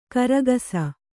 ♪ karagasa